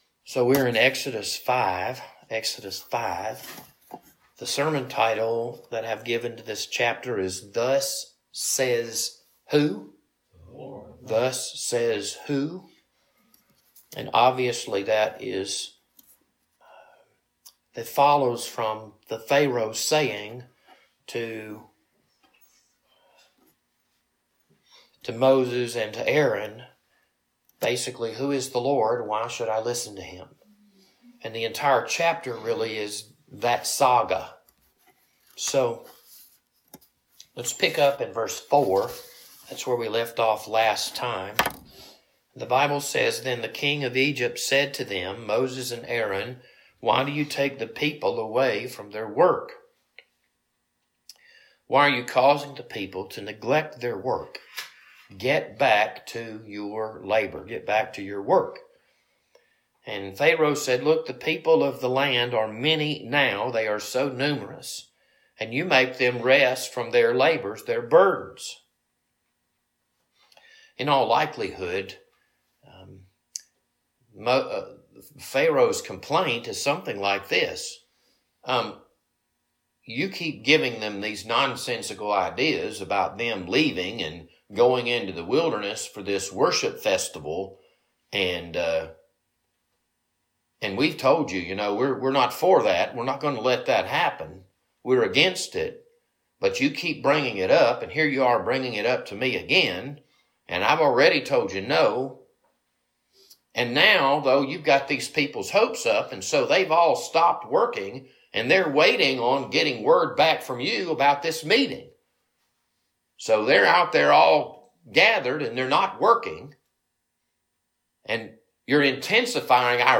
This Wednesday evening Bible study was recorded on March 5th, 2025.